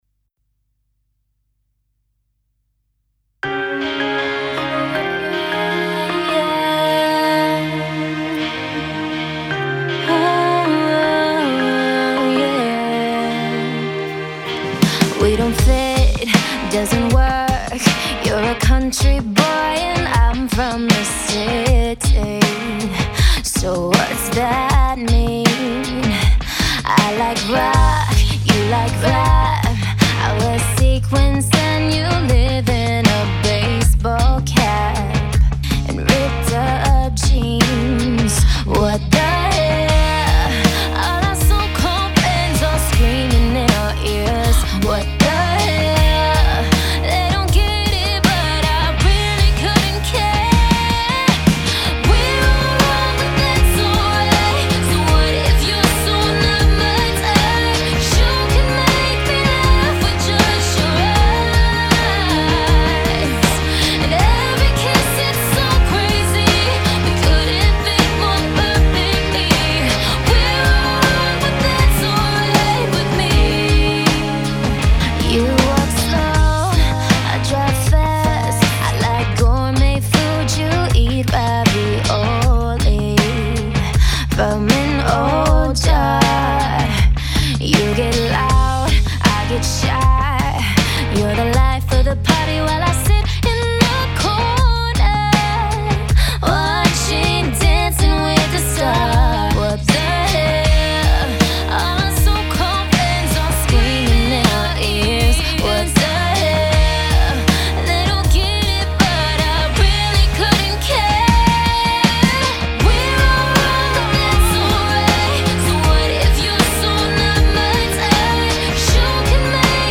Midtempo pop rock Fm vx